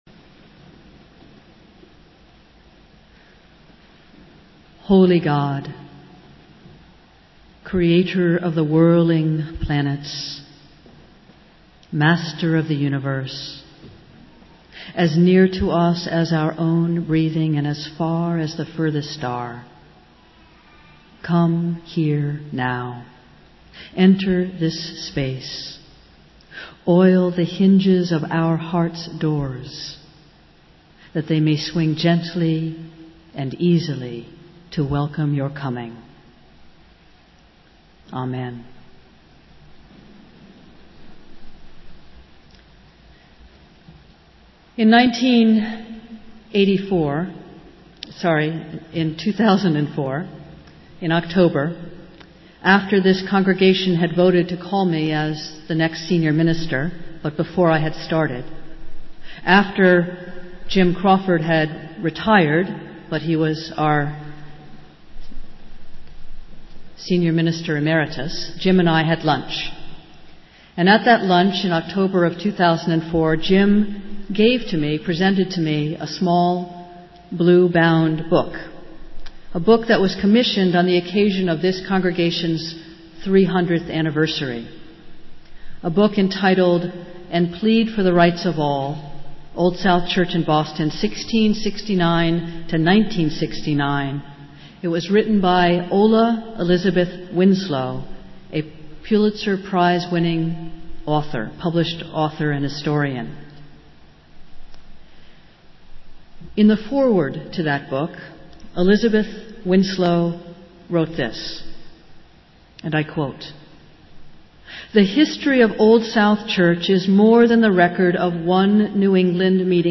Festival Worship - Third Sunday after Pentecost